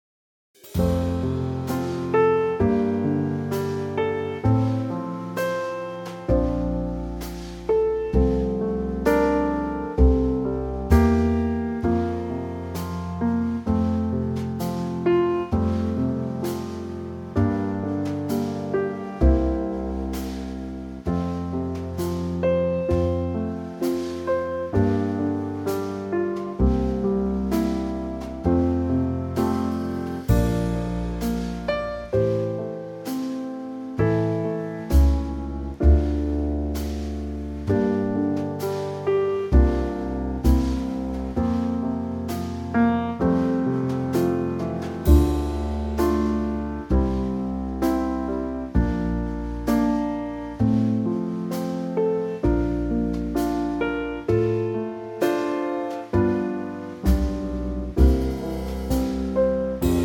Unique Backing Tracks
4 bar intro and vocal in at 15 seconds
key - F to G - vocal range - C to G
A superb Trio arrangement